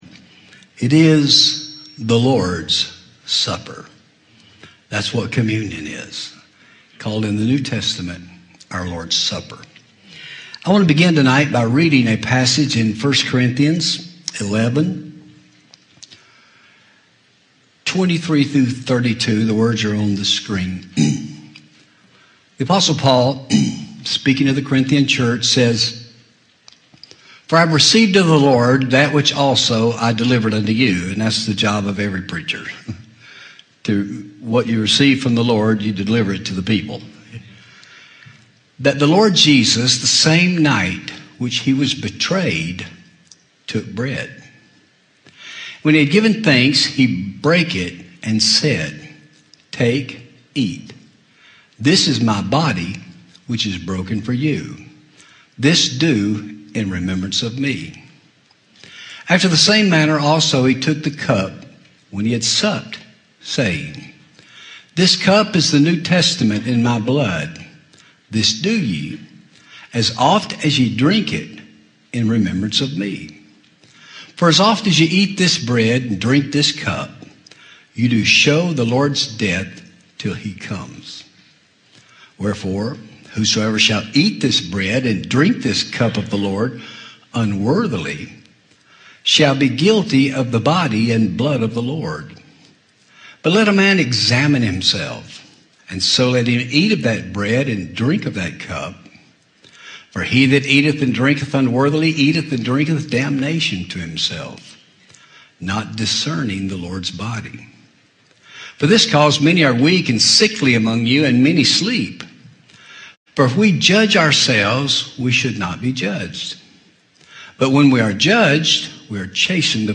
Christmas Communion 2019 Audio Sermon